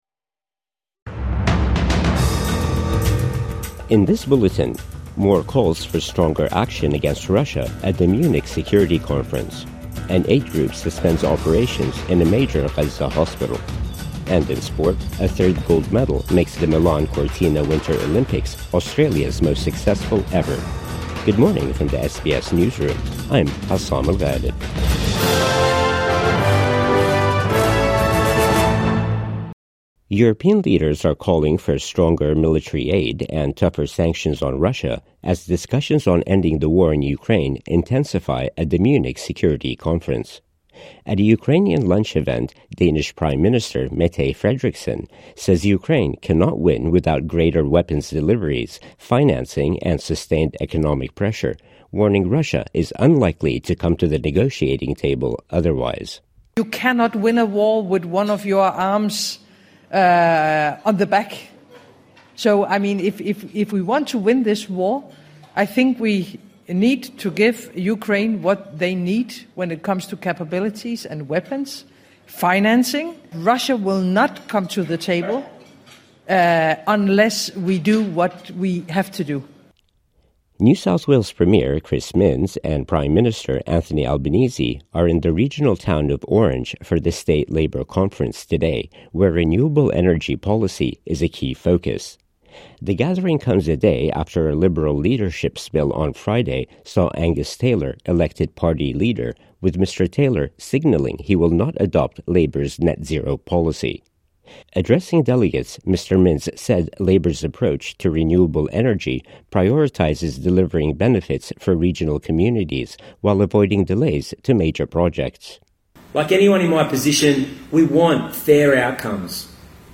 More calls for stronger action against Russia at Munich conference | Morning News Bulletin 15 February 2026